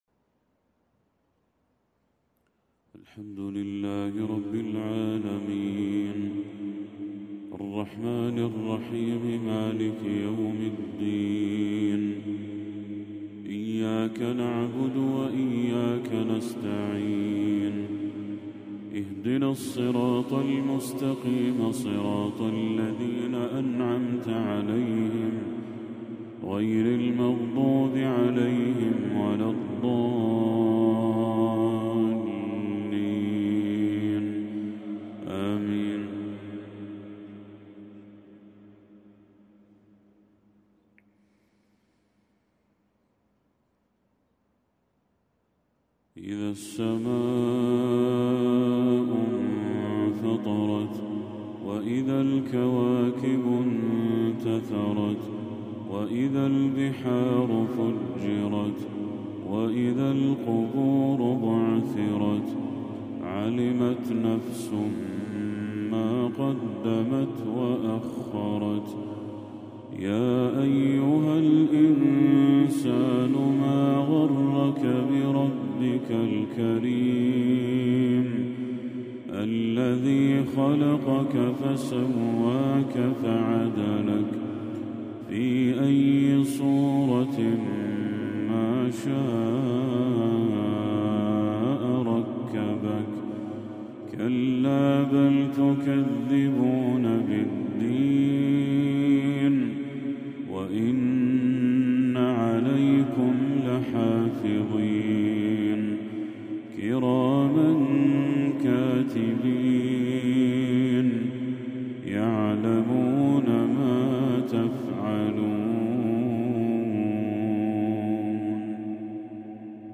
تلاوة لسورة الانفطار للشيخ بدر التركي | مغرب 27 ربيع الأول 1446هـ > 1446هـ > تلاوات الشيخ بدر التركي > المزيد - تلاوات الحرمين